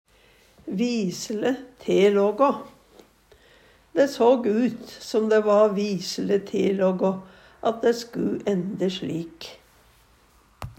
DIALEKTORD PÅ NORMERT NORSK visele telågå skjebne Eksempel på bruk Dæ såg ut som dæ va visele telågå at dæ sku ænde slik.